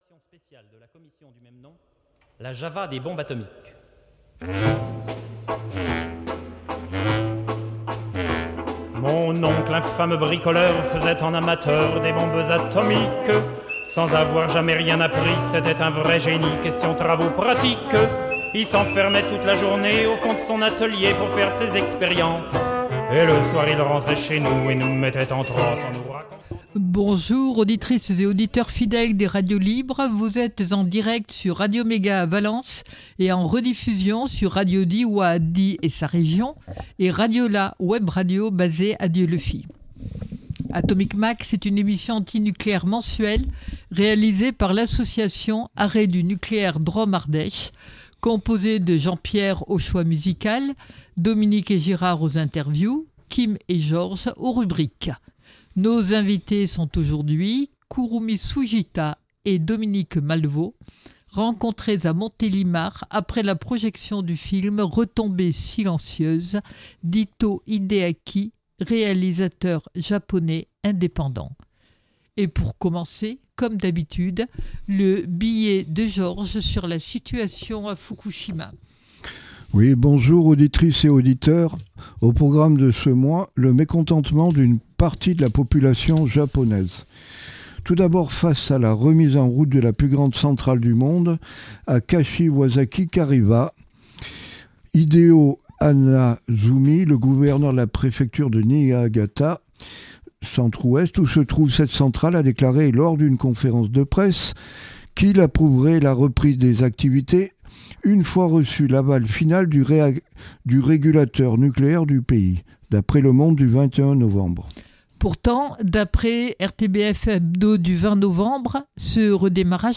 Atomic Mac, c’est l’émission radio mensuelle de l’association Arrêt du nucléaire Drôme-Ardèche.